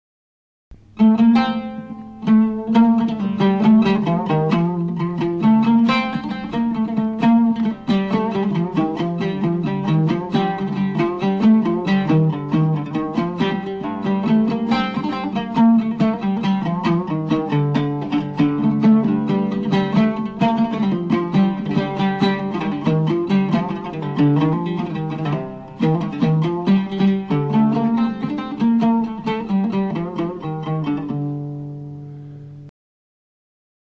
(Samaii Araby speakicon).  We notice that this Samaii is missing the rhythmic variation in the fourth part, and is a simple composition.